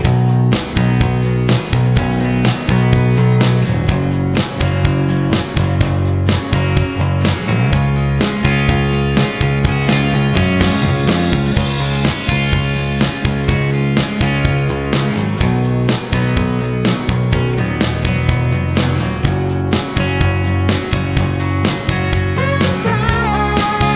Solo Cut Down Rock 5:38 Buy £1.50